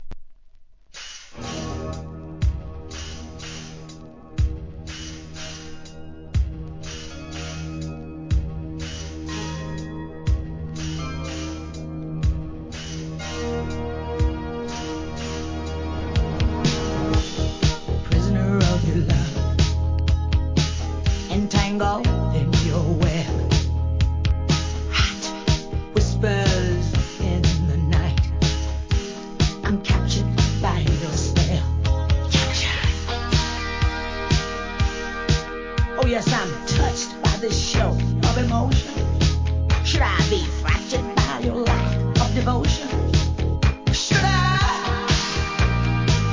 ¥ 440 税込 関連カテゴリ SOUL/FUNK/etc...